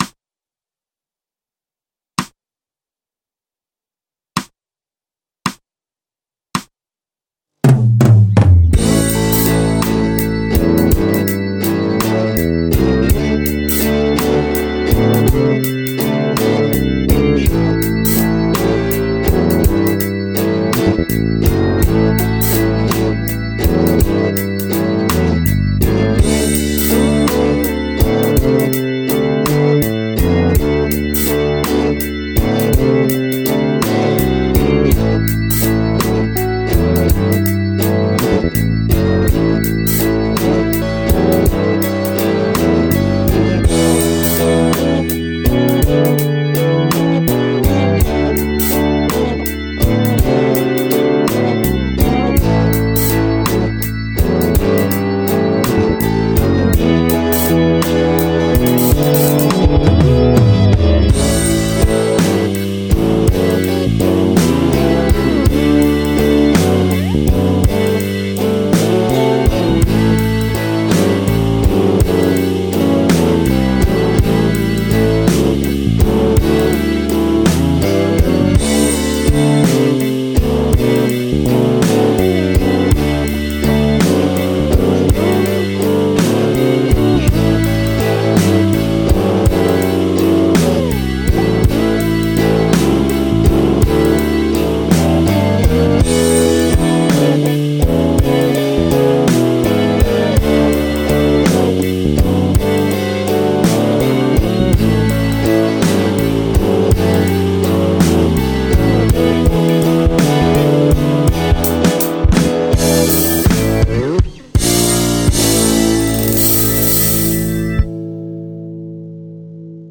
ブルース・ペンタトニック・スケール ギタースケールハンドブック -島村楽器